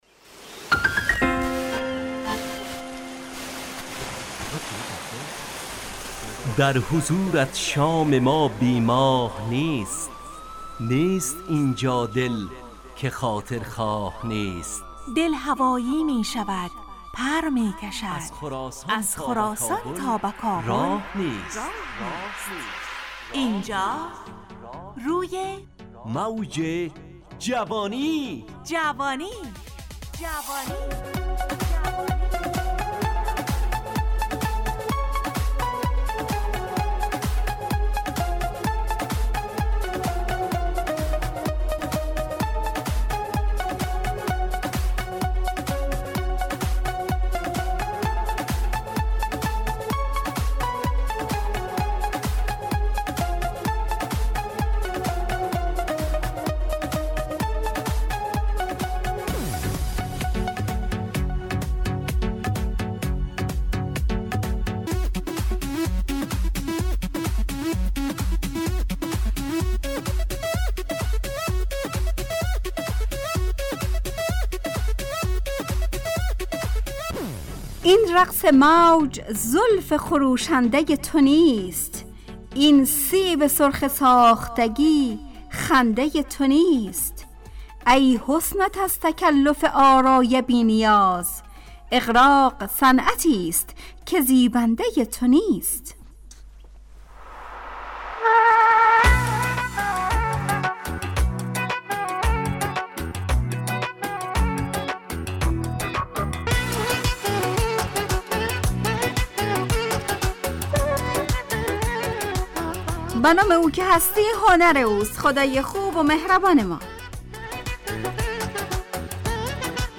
همراه با ترانه و موسیقی مدت برنامه 55 دقیقه . بحث محوری این هفته (هنر) تهیه کننده